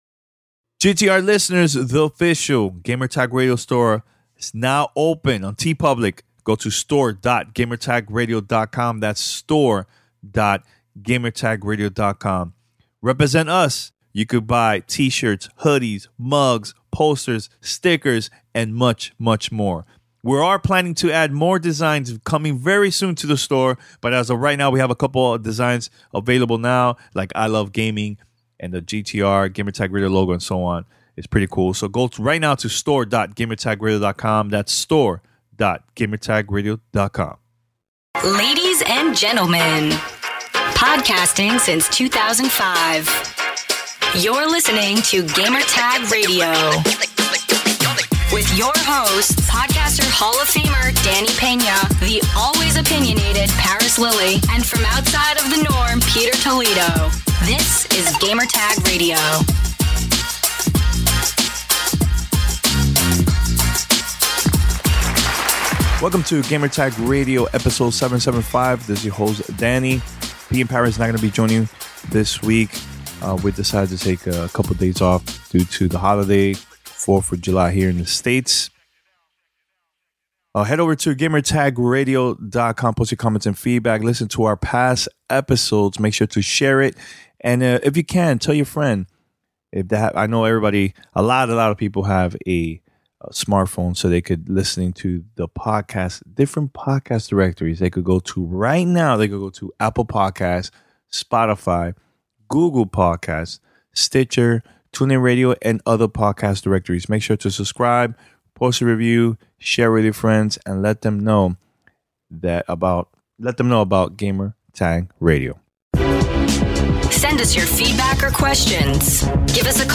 Roundtable discussion about Nintendo Switch with NinMobileNews. Attending E3 for the first time, content creator, best indie games and much more.